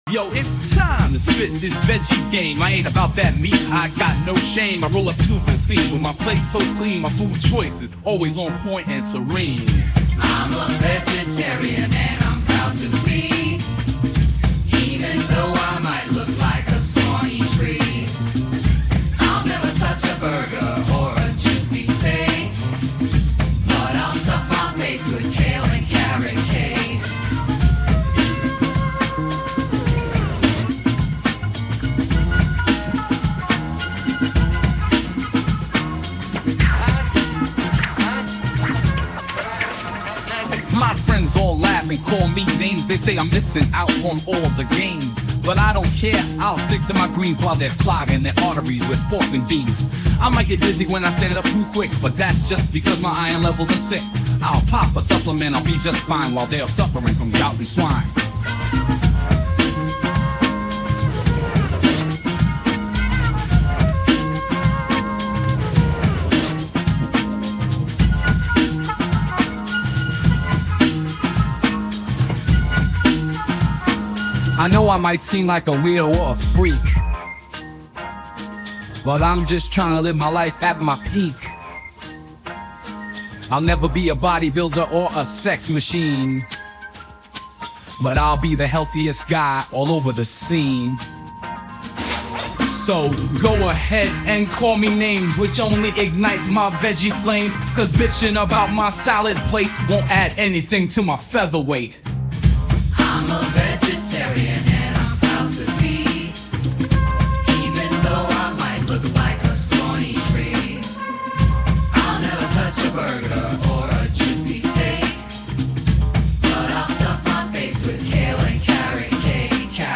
Finally, I made some minor tweaks to the music (mainly responsible for the little imperfections you might hear) with the help of audacity.
Version 2: Music by Udio (V1)